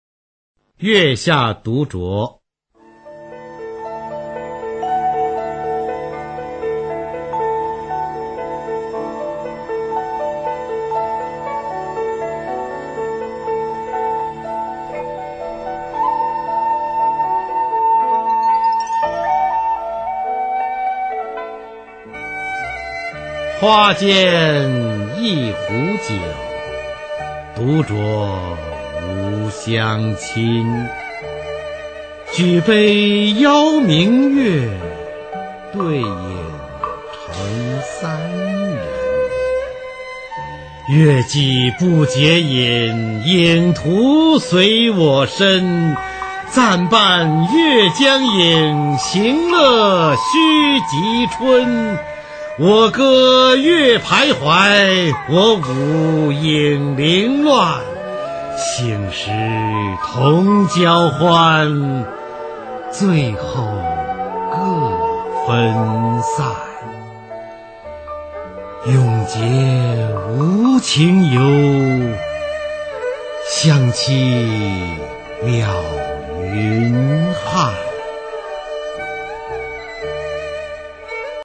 [隋唐诗词诵读]李白-月下独酌 古诗文诵读